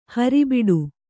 ♪ hari biḍu